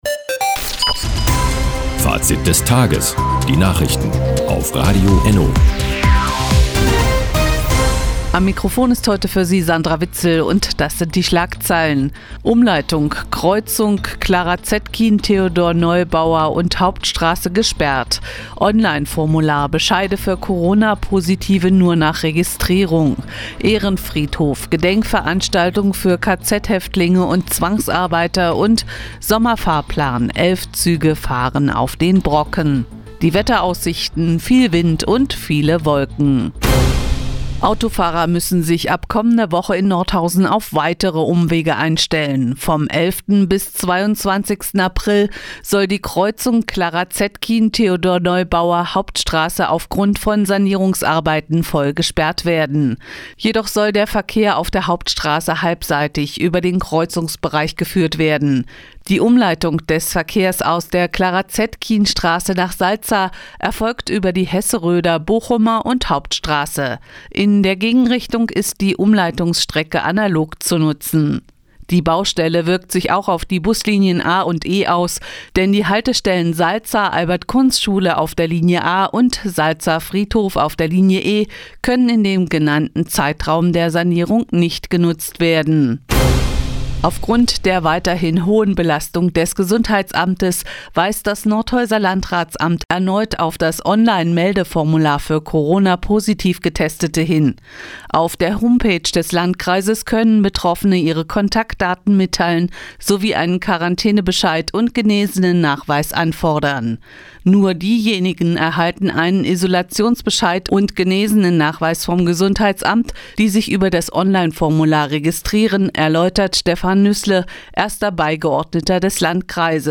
Die tägliche Nachrichtensendung ist jetzt hier zu hören...